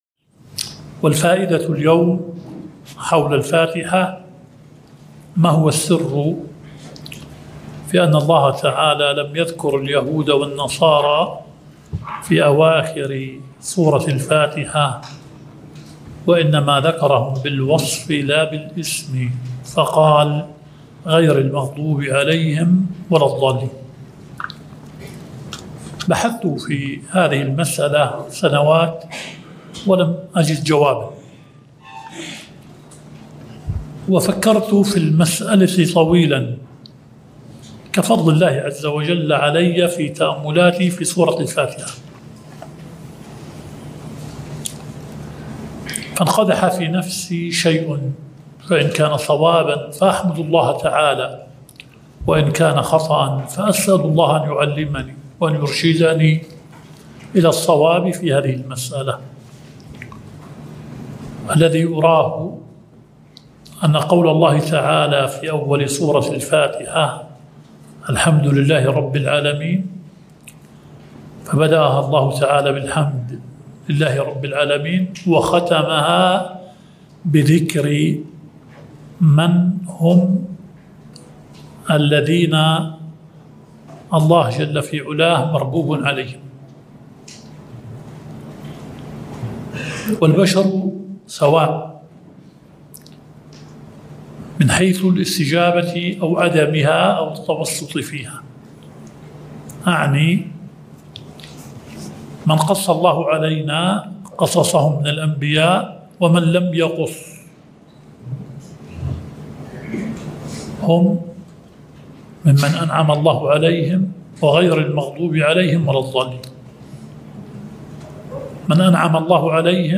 المحاضرة 1